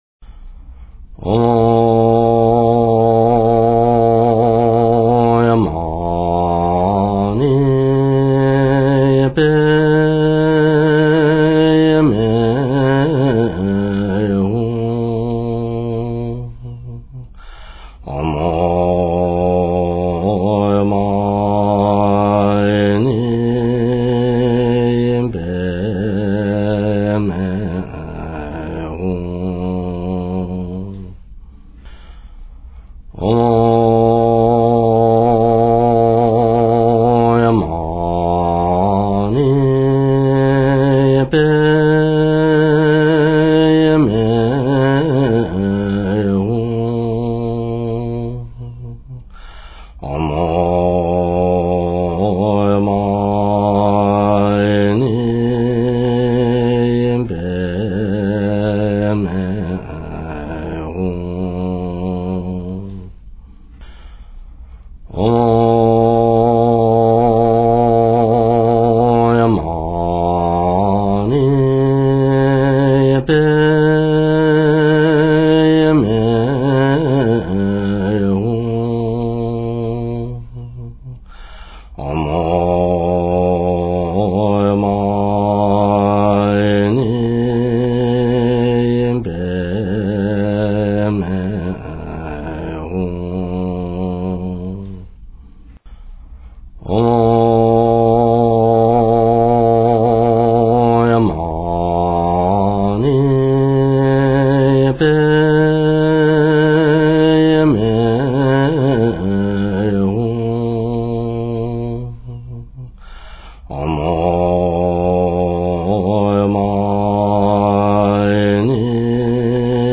Om Mani Padme Hum - Kyabje Lama Zopa Rinpoche - slow B
Om Mani Padme Hum - Kyabje Lama Zopa Rinpoche - slow B.mp3